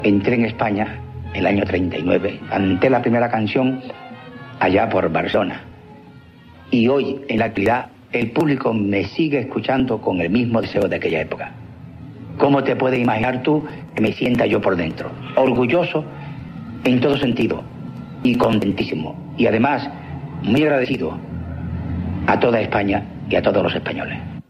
El cantant Antonio Machín recorda la seva arribada a Espanya i la bona acollida que van tenir les seves cançons a la ràdio
Programa presentat per Joan Manuel Serrat